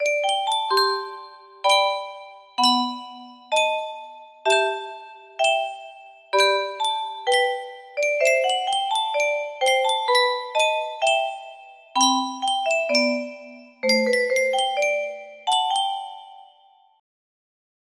Clone of Yunsheng Music Box - Tsunami 1395 music box melody